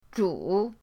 zhu3.mp3